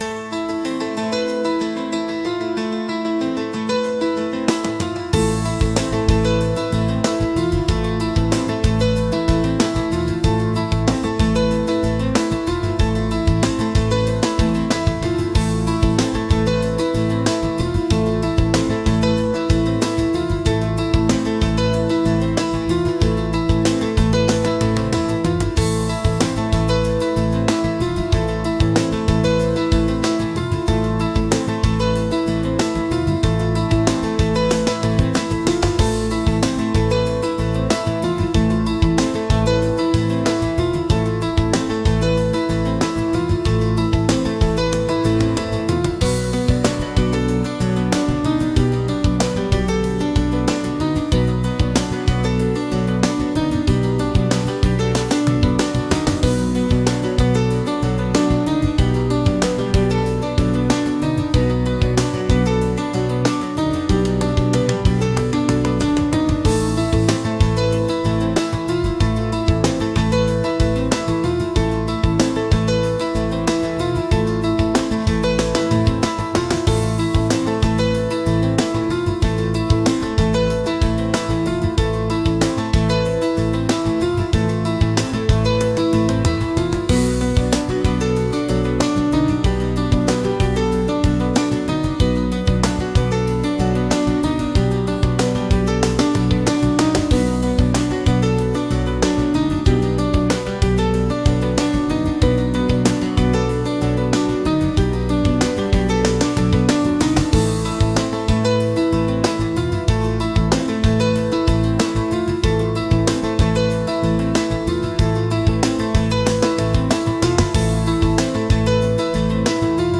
シリアス